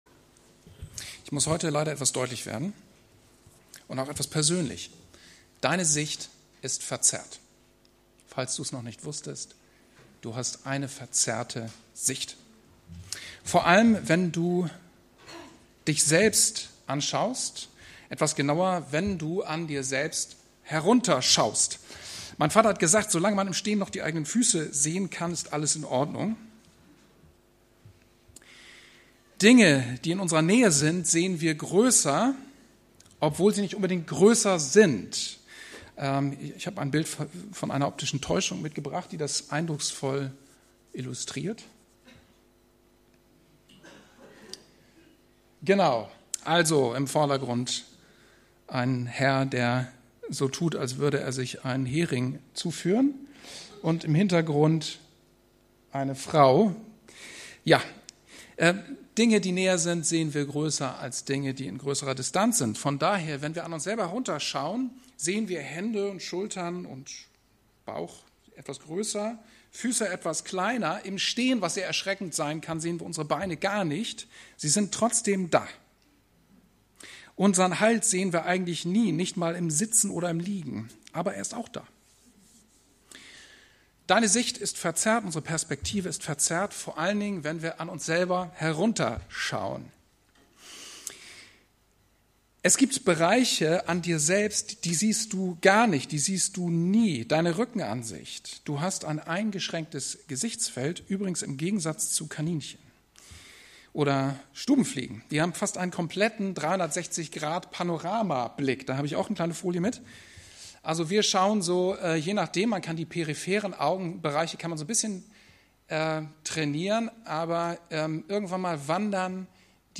Die Online-Präsenz der freien evangelischen Andreas-Gemeinde Osnabrück
PREDIGTEN